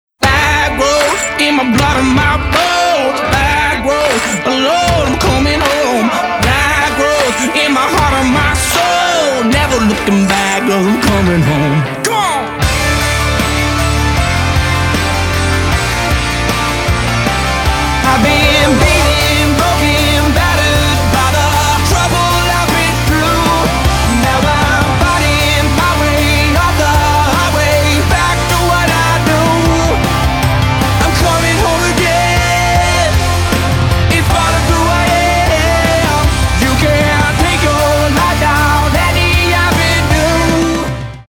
• Качество: 320, Stereo
громкие
Alternative Rock
country rock
мужcкой вокал